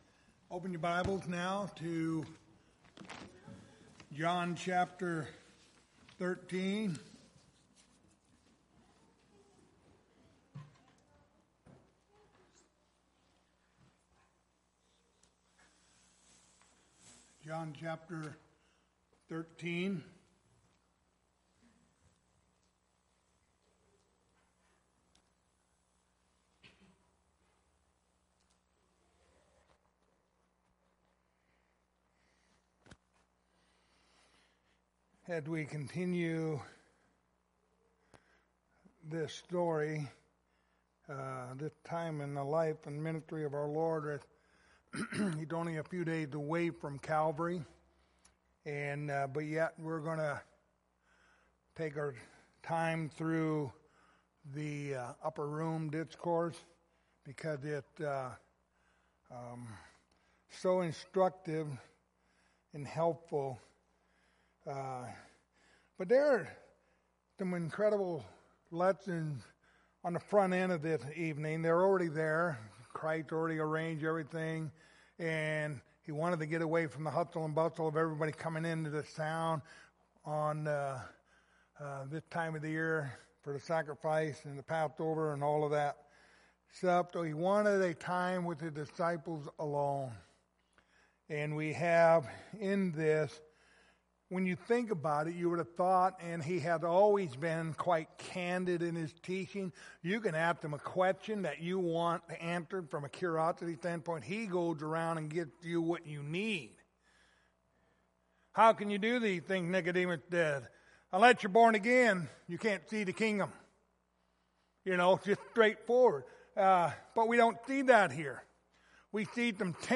The Gospel of John Passage: John 13:12-17 Service Type: Wednesday Evening Topics